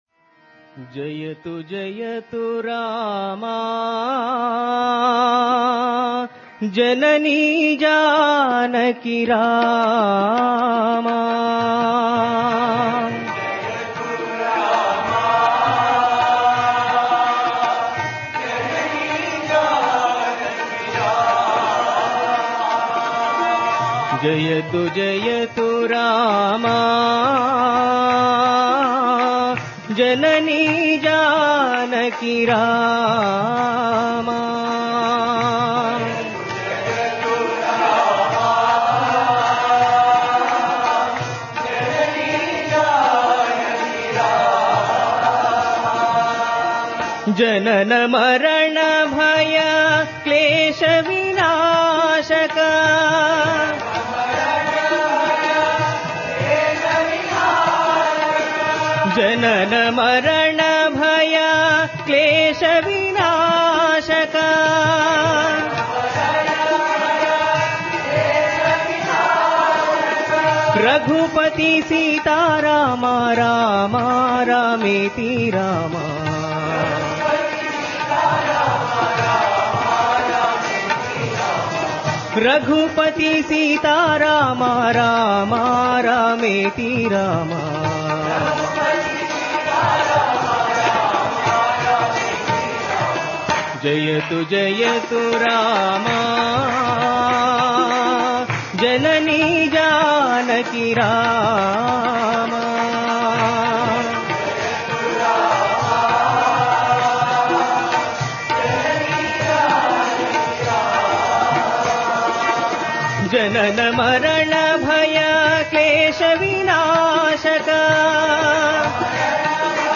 Related Bhajan